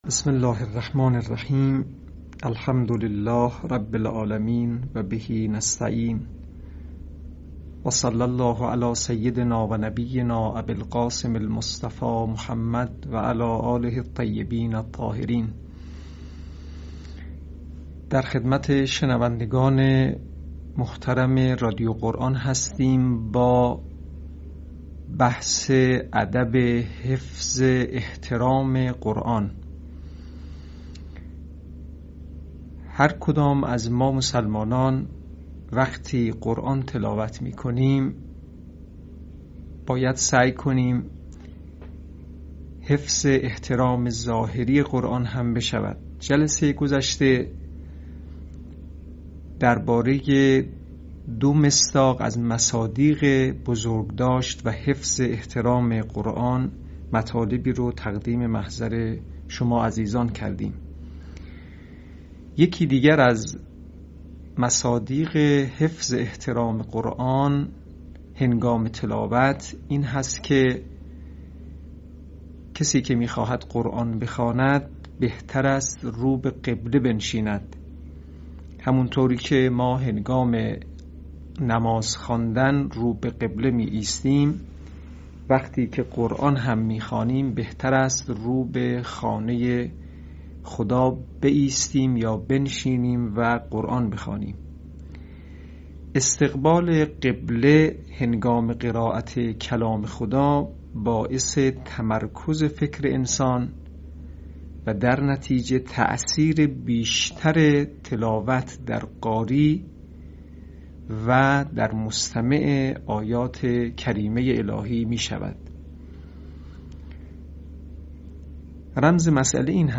آموزش آداب تلاوت